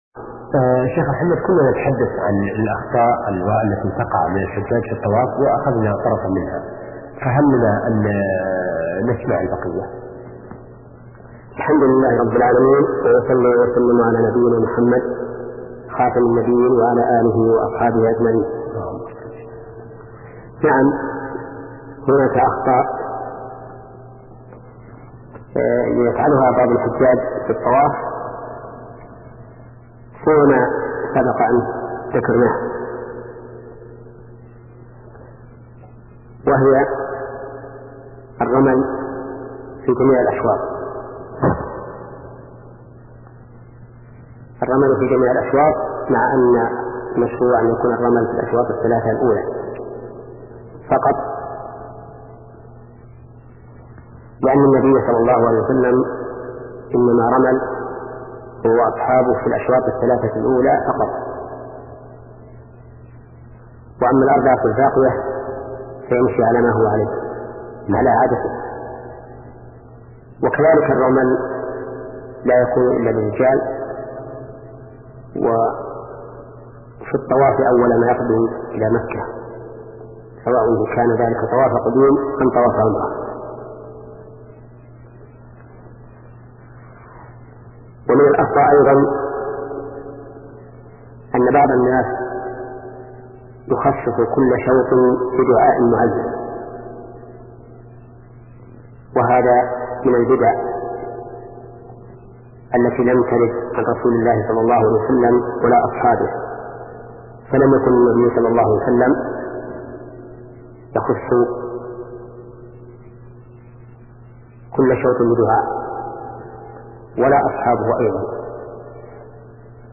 شبكة المعرفة الإسلامية | الدروس | سلسلة فقه العبادات 10 |محمد بن صالح العثيمين